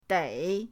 dei3.mp3